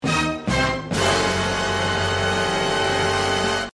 efecto-de-sonido-sorprendido.mp3